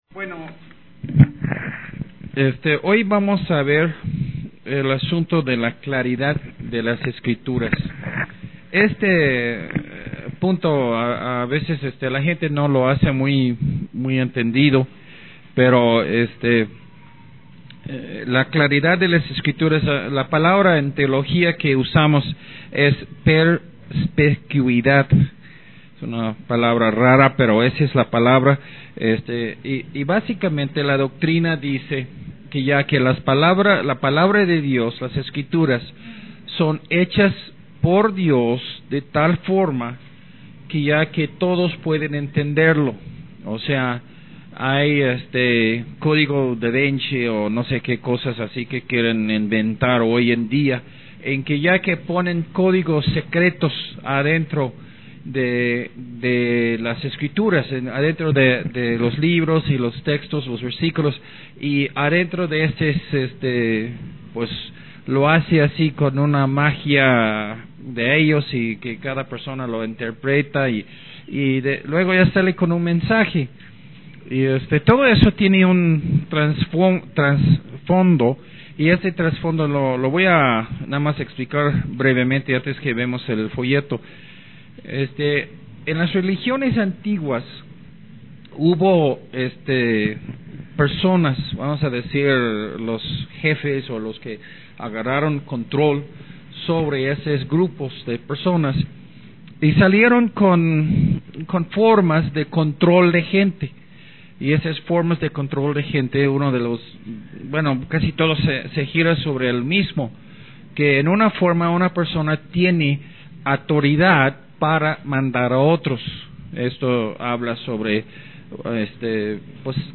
sermón de audio